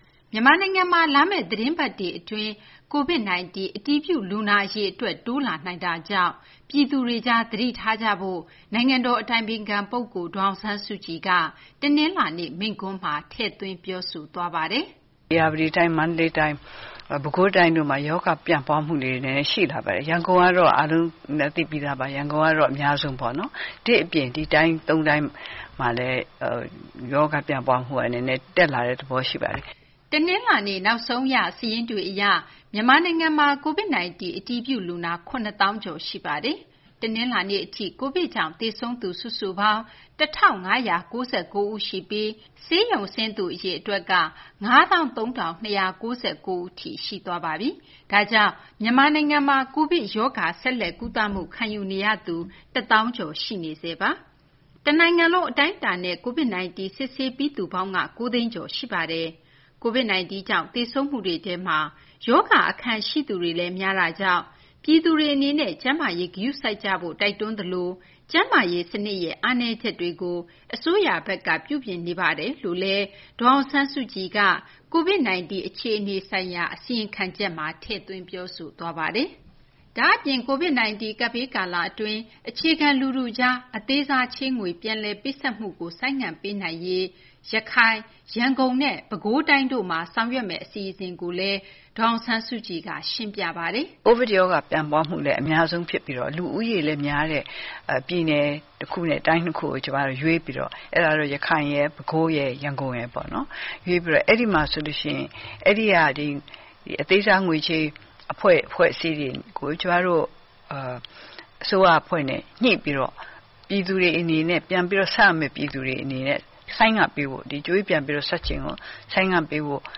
ရွေးကောက်ပွဲကာလအတွင်း လူစု၊ လူဝေးဖြစ်ခဲ့တဲ့ အရှိန်ကြောင့် လာမယ့် သီတင်းပတ်တွေ အတွင်း မြန်မာနိုင်ငံမှာ COVID 19 ကူးစက်မှုနှုန်း တိုးလာဖို့ ရှိနေကြောင်း နိုင်ငံတော် အတိုင်ပင်ခံ ပုဂ္ဂိုလ် ဒေါ်အောင်ဆန်းစုကြည်က တနလာၤနေ့ မိန့်ခွန်းမှာ သတိပေးပြောကြားခဲ့ပါတယ်။